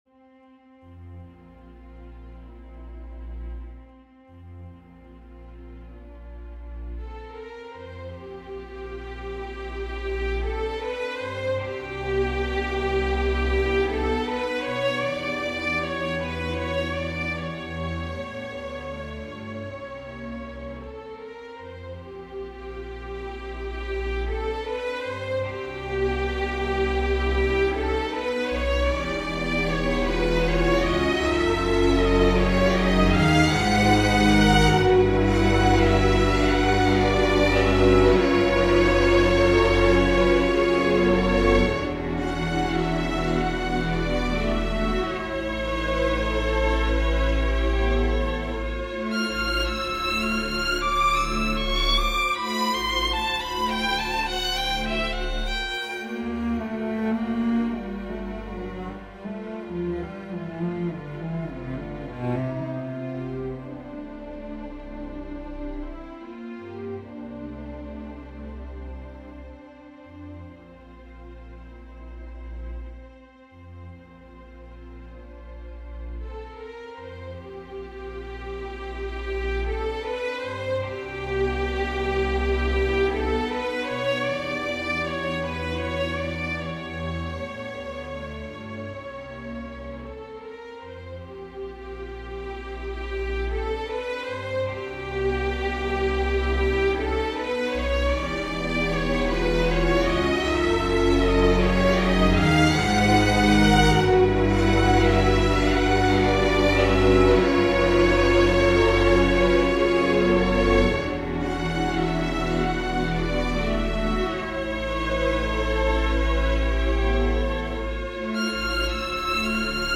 A Moment of Repose - Chamber Music - Young Composers Music Forum
Although microtonal, this piece was intended to be a little more palatable harmonically. I use 1/6th and 1/3rd tones so I guess technically it's 36 tet.